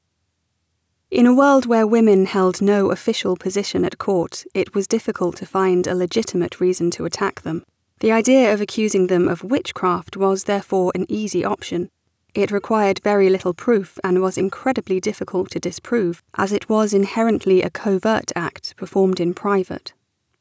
Special Interest Groups Audiobook Production
The second is the same audio with the mastering macro applied.
I’m using a Rode NT1-A mic with U22-XT interface. I’m recording in the cupboard under the stairs, which I’ve converted into a booth with acoustic foam and heavy blankets.
I notice that there is a very low frequency hum at 82 Hz.
Your delivery is, in my opinion, a little too crisp and bright.
There is skipping, which is audible, most noticeable in the “silence” …
I don’t notice any “whistling” or over-emphasis of S’s, just a clear and crisp recording.